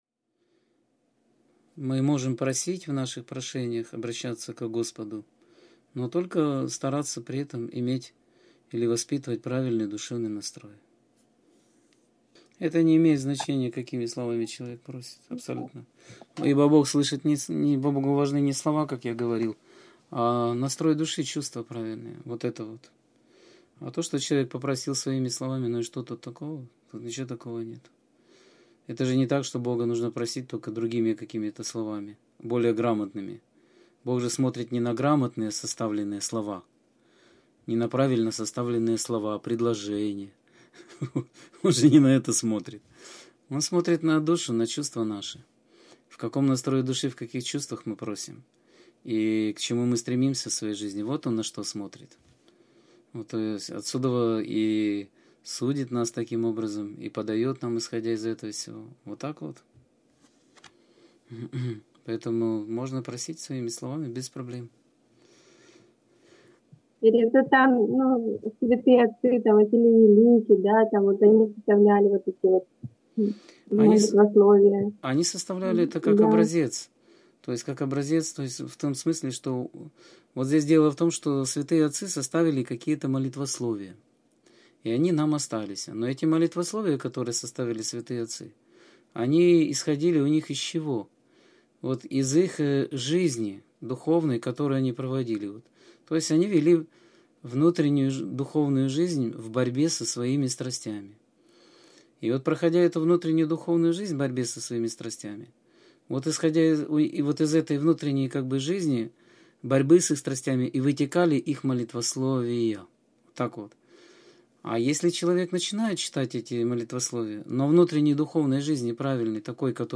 Скайп-беседа 4.10.2014